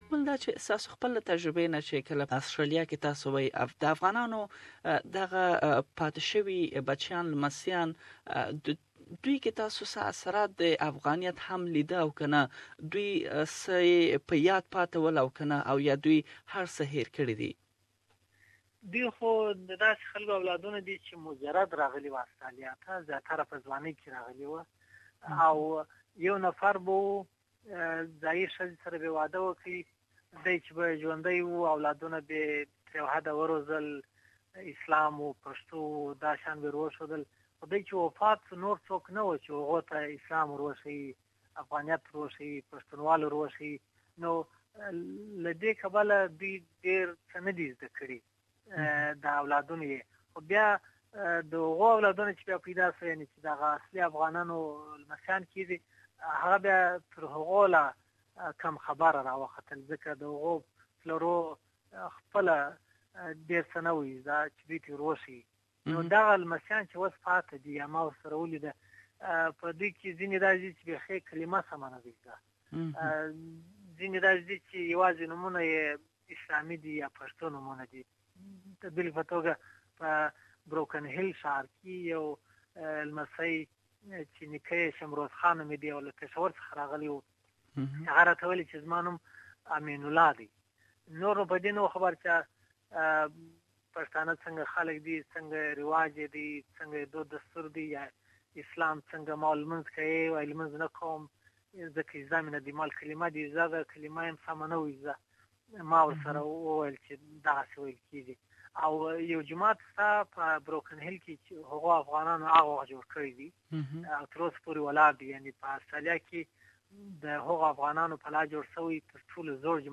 Please listen to the 2nd part of his exclusive interview here